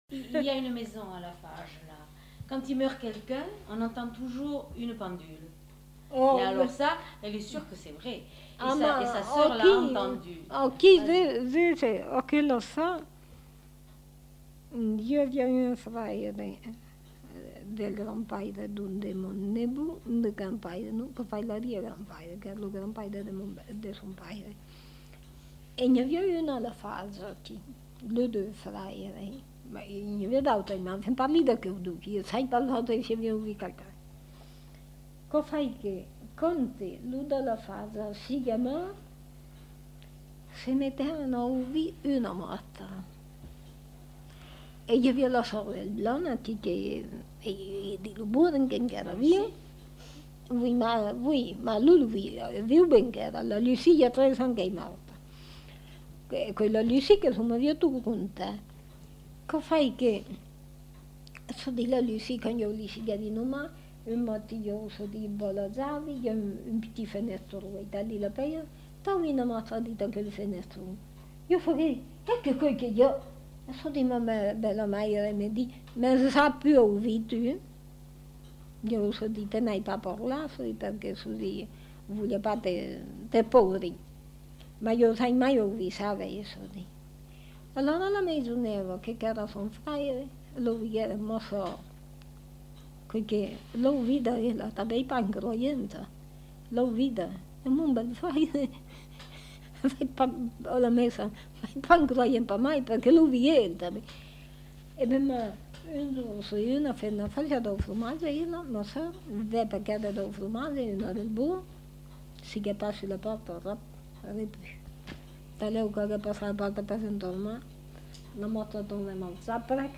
Aire culturelle : Périgord
Lieu : La Chapelle-Aubareil
Genre : conte-légende-récit
Effectif : 1
Type de voix : voix de femme
Production du son : parlé